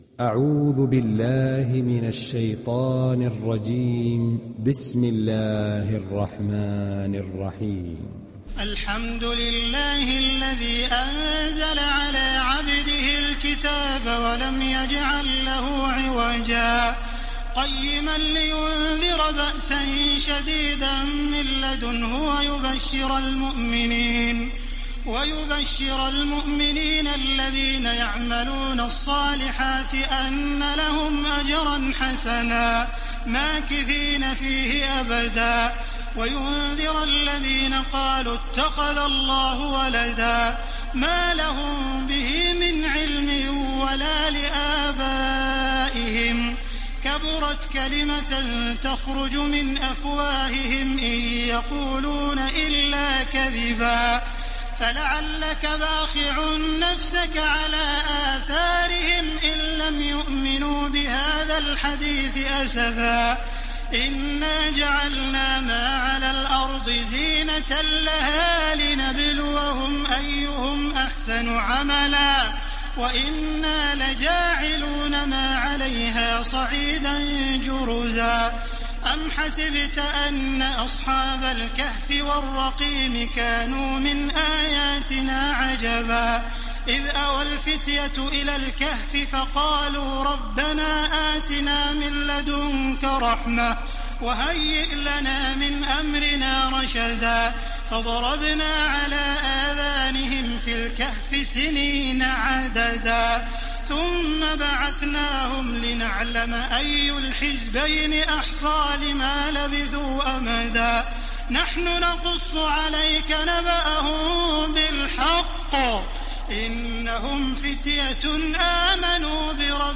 تحميل سورة الكهف mp3 بصوت تراويح الحرم المكي 1430 برواية حفص عن عاصم, تحميل استماع القرآن الكريم على الجوال mp3 كاملا بروابط مباشرة وسريعة
تحميل سورة الكهف تراويح الحرم المكي 1430